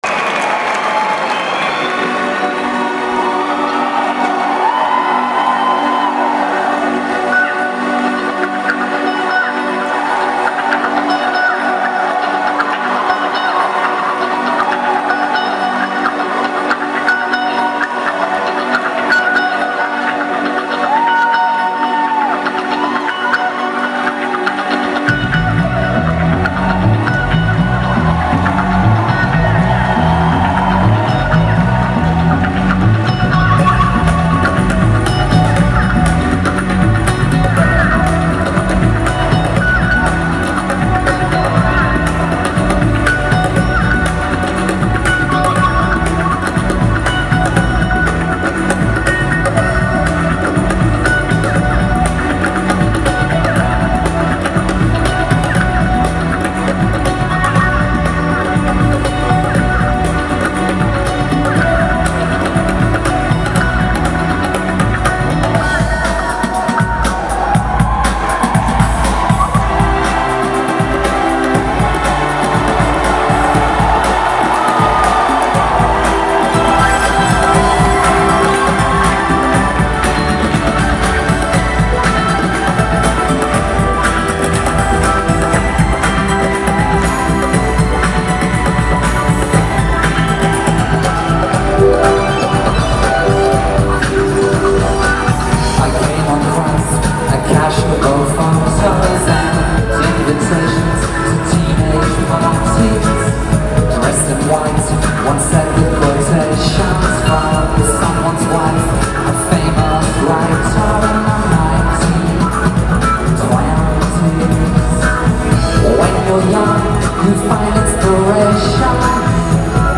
21st May, 2002 New York City USA
(Low quality,
recorded from audience, 10 MB)